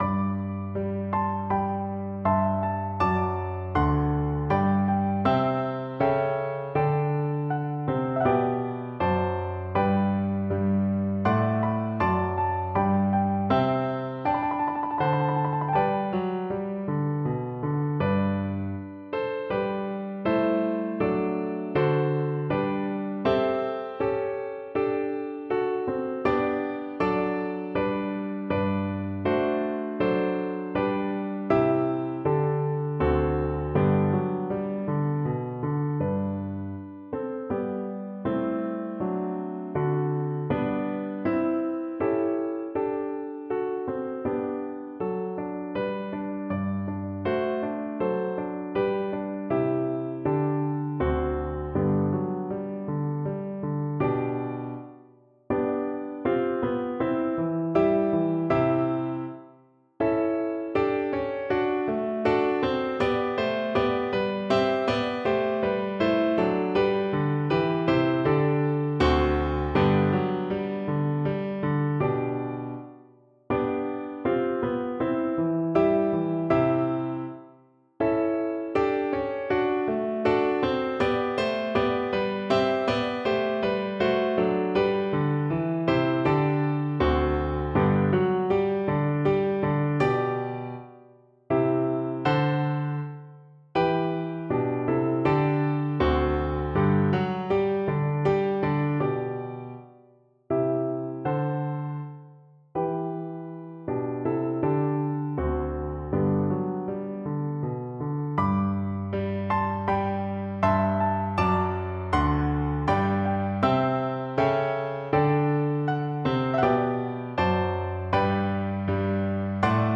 Free Sheet music for Voice
G major (Sounding Pitch) (View more G major Music for Voice )
Andante =80
3/4 (View more 3/4 Music)
E5-G6
Classical (View more Classical Voice Music)
Italian Baroque Songs for Soprano